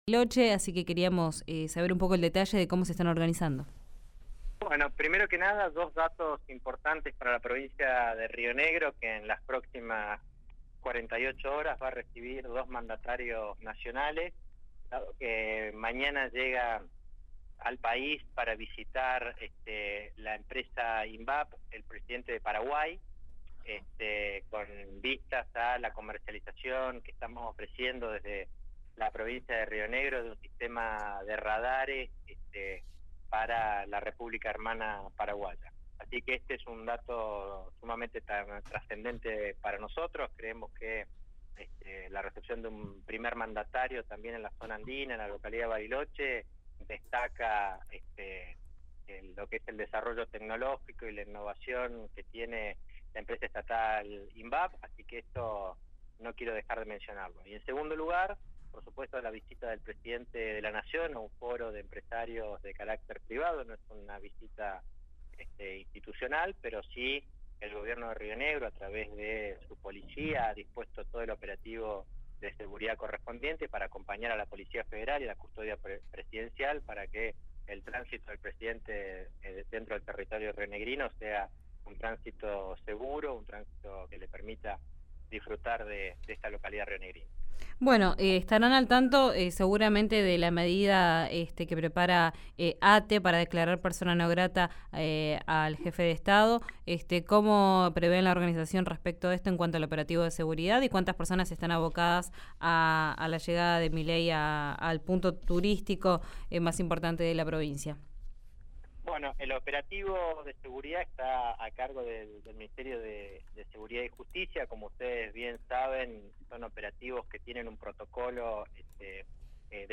Escuchá al ministro Federico Lutz en RÍO NEGRO RADIO
El ministro de Gobierno, Trabajo, Modernización y Turismo de Río Negro, Federico Lutz, habló con los micrófonos de RÍO NEGRO RADIO y se refirió a la seguridad del presidente Javier Milei que el viernes visitará Bariloche por el Foro Llao Llao y diversos sectores organizaron protestas.